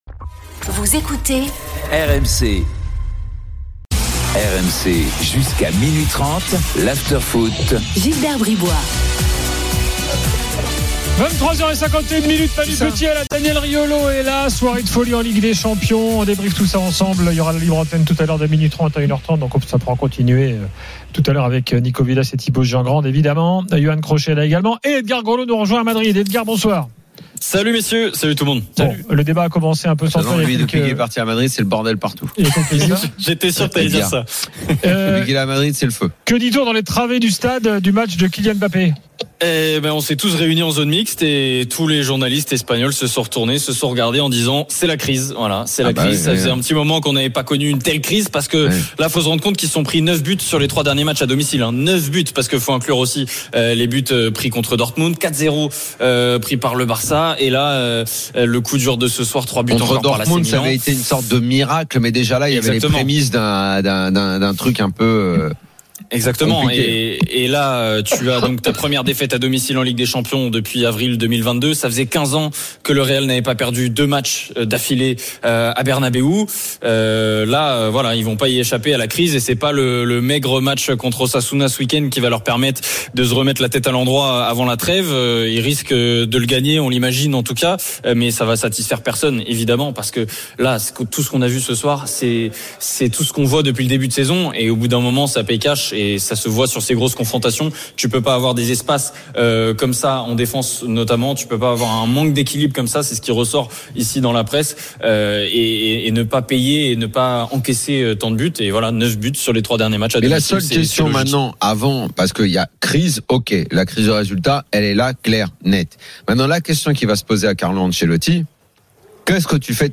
Chaque jour, écoutez le Best-of de l'Afterfoot, sur RMC la radio du Sport !
avec les réactions des joueurs et entraîneurs, les conférences de presse d’après-match et les débats animés entre supporters, experts de l’After et auditeurs RMC.